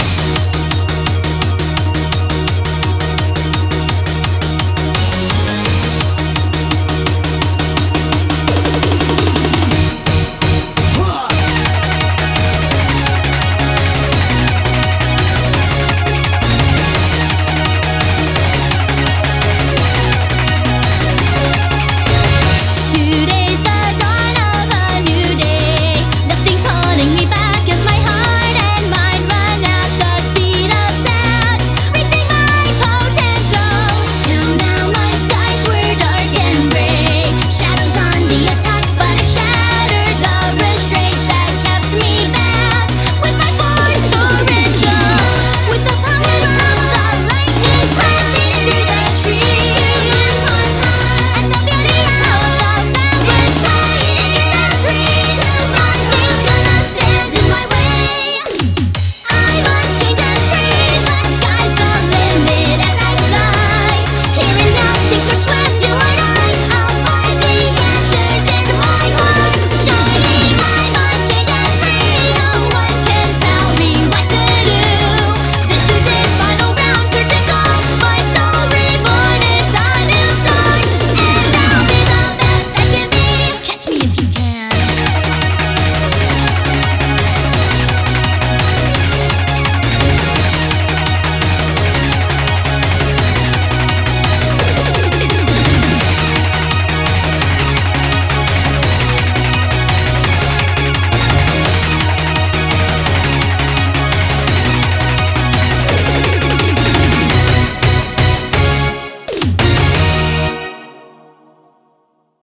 BPM170
Comments[POWER EUROBEAT]